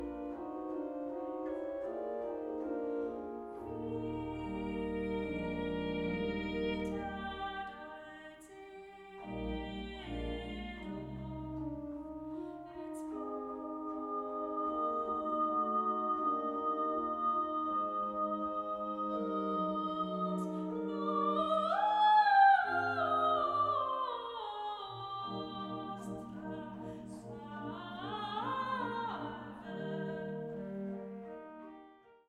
Klassische Kirchenkonzerte